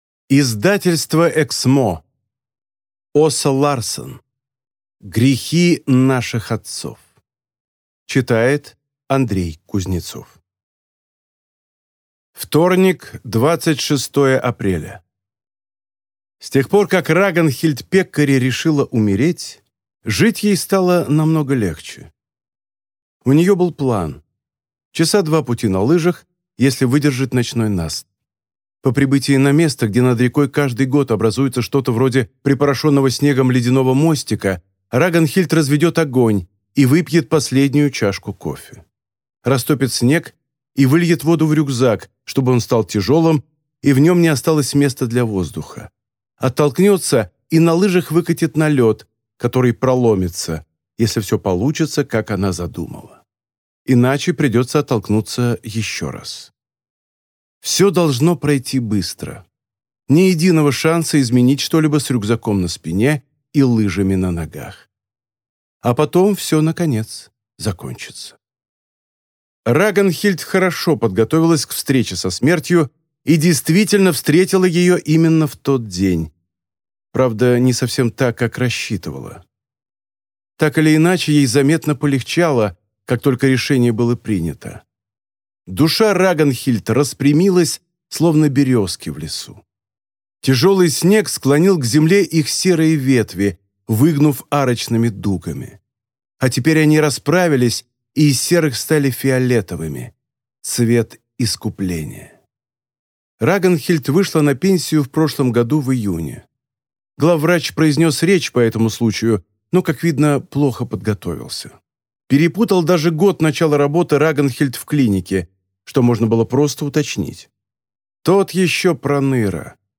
Аудиокнига Грехи наших отцов | Библиотека аудиокниг